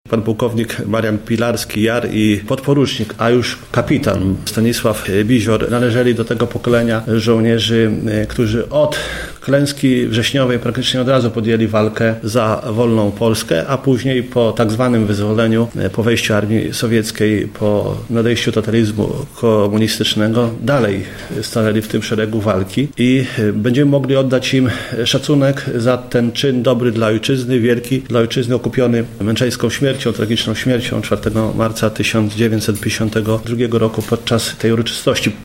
Więcej o obu bohaterach mówi poseł Sławomir Zawiślak